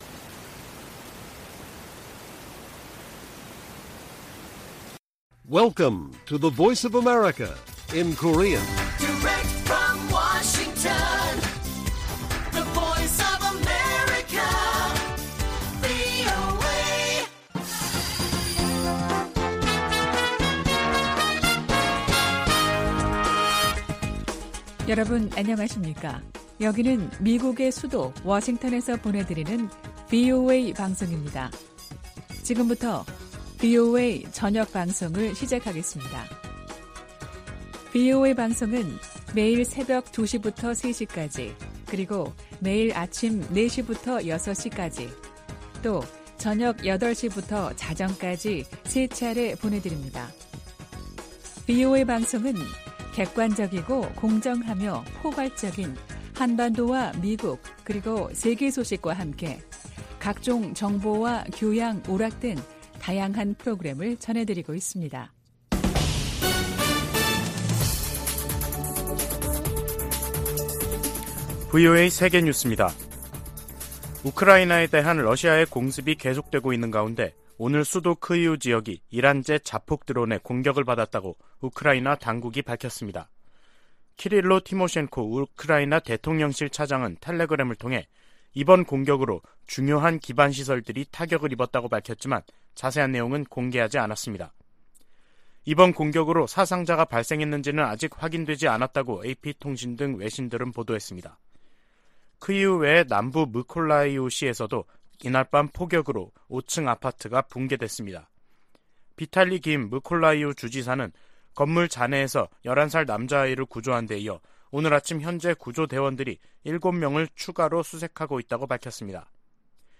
VOA 한국어 간판 뉴스 프로그램 '뉴스 투데이', 2022년 10월 13일 1부 방송입니다. 북한이 핵운용 장거리 순항 미사일을 시험발사했습니다.